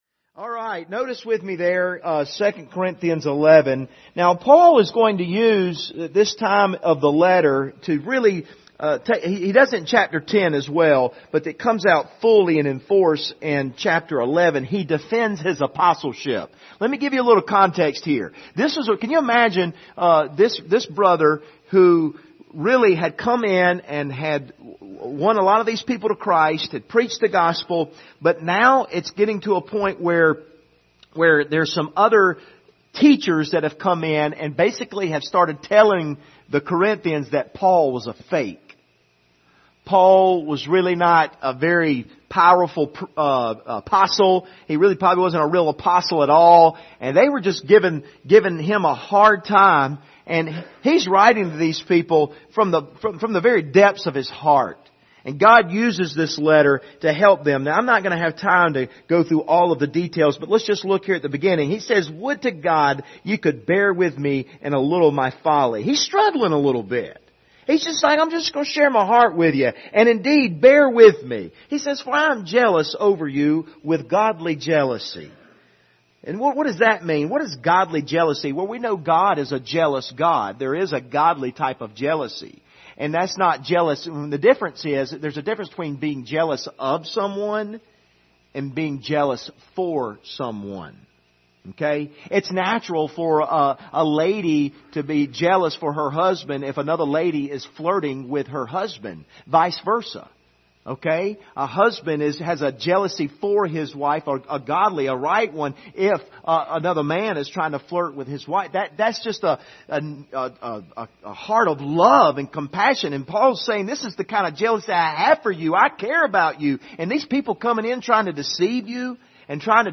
Passage: 2 Corinthians 11:1-9 Service Type: Sunday Evening